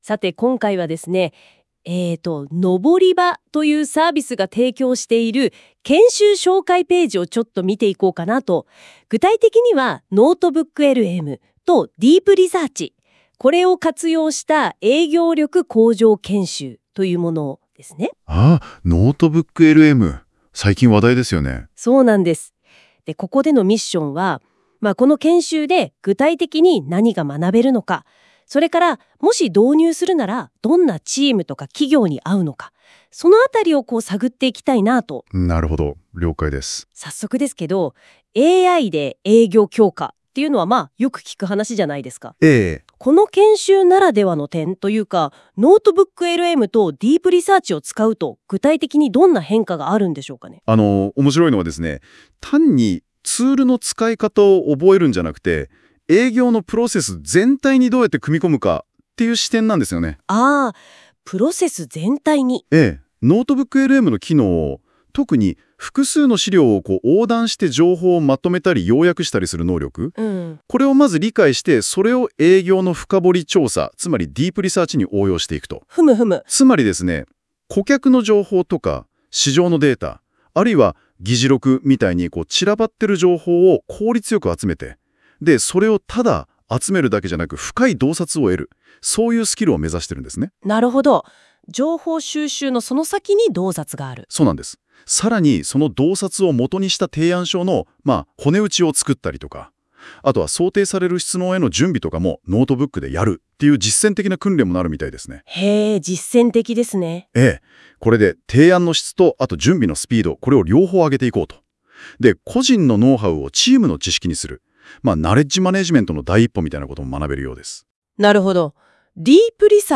音声概要（研修内容）
このような音声を作れるようになります